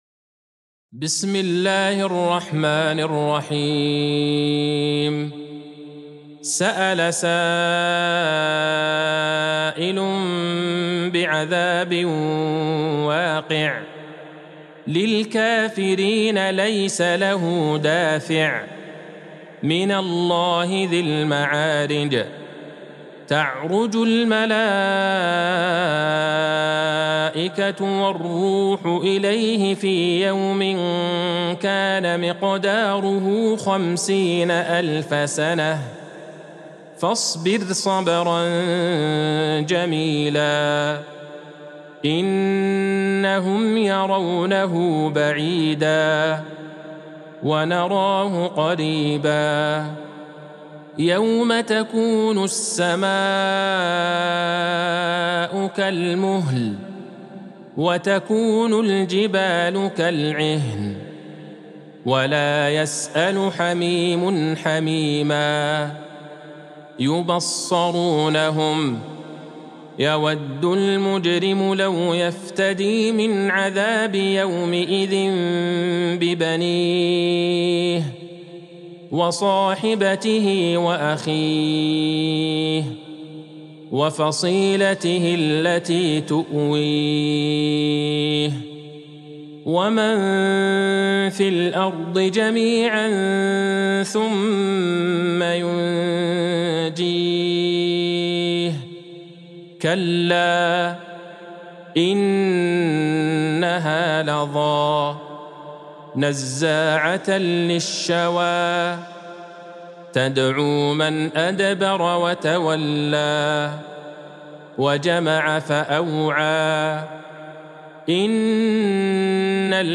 سورة المعارج Surat Al-Maarij | مصحف المقارئ القرآنية > الختمة المرتلة ( مصحف المقارئ القرآنية) للشيخ عبدالله البعيجان > المصحف - تلاوات الحرمين